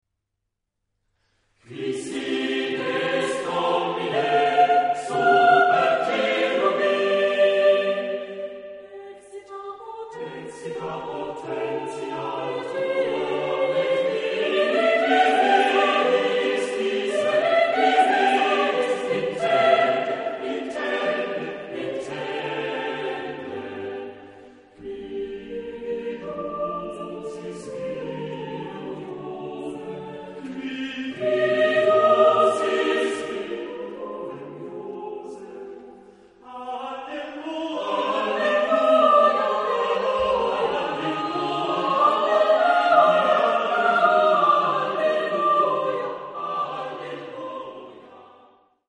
Genre-Style-Forme : Sacré ; Hymne (sacré) ; Romantique
Type de choeur : SATB  (4 voix mixtes )
Tonalité : do majeur